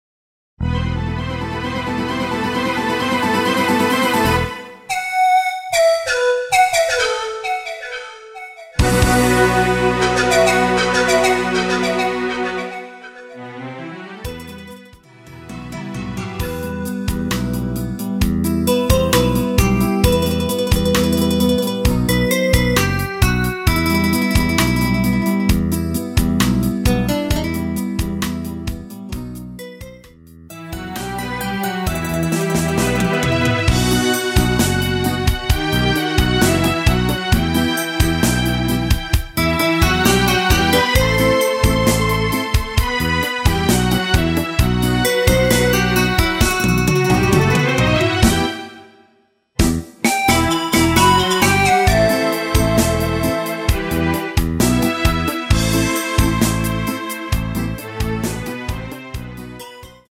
Em
앞부분30초, 뒷부분30초씩 편집해서 올려 드리고 있습니다.
중간에 음이 끈어지고 다시 나오는 이유는